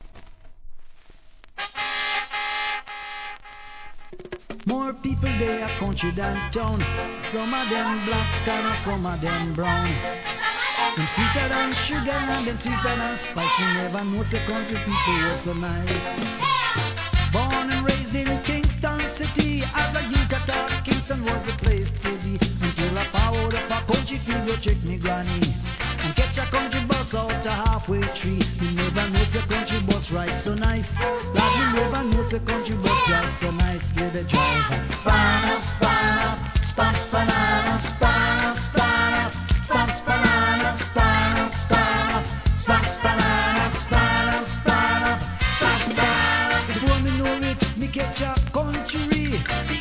REGGAE
子供達をバックコーラスになかなか楽しい曲に仕上がっています!!